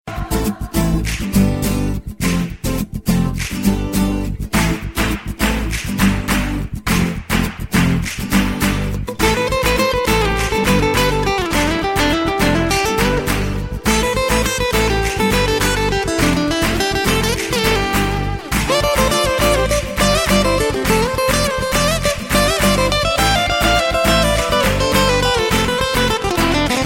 • Качество: 128, Stereo